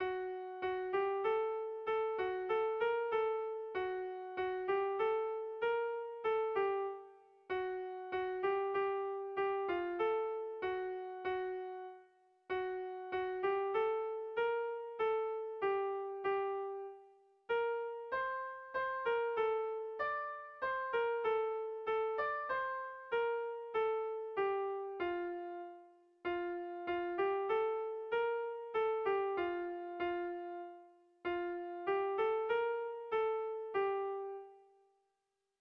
Zortziko txikia (hg) / Lau puntuko txikia (ip)
AABA2